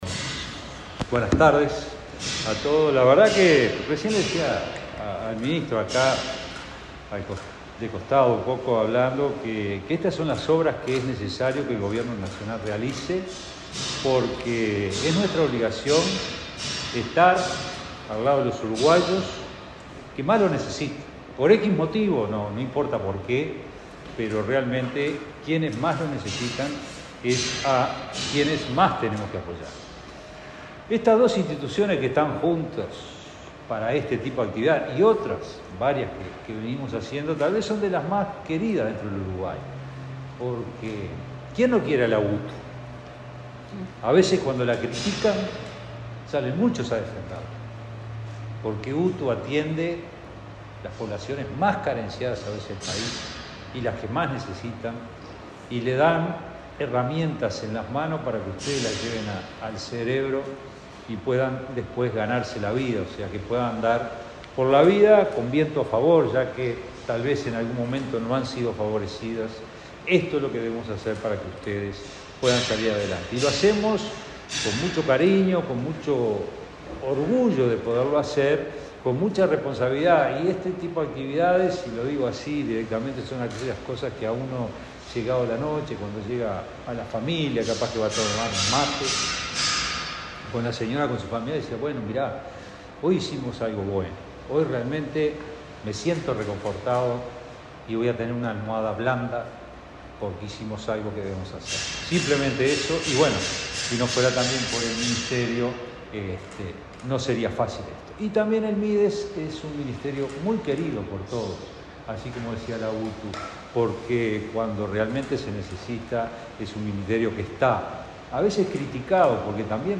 Palabras de autoridades en acto de UTU y Mides